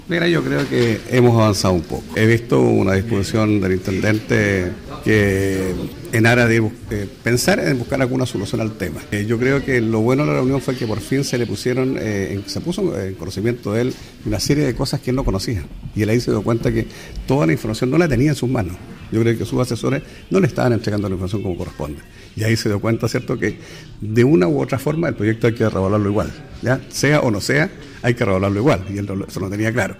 En conversación con Radio Bío Bío, el edil local señaló que se trató de una instancia más que provechosa y esclarecedora, ya que sirvió para que se pongan sobre la mesa todos los antecedentes surgidos tras la paralización de las obras.